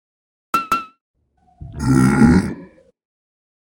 fire-2.ogg.mp3